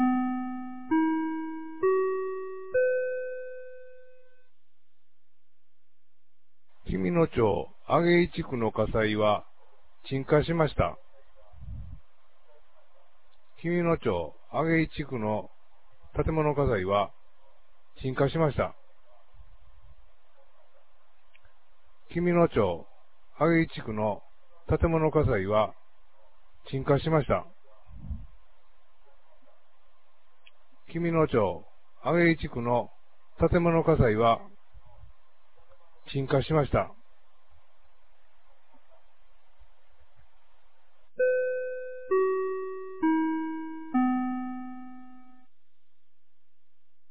2023年06月03日 20時15分に、紀美野町より全地区へ放送がありました。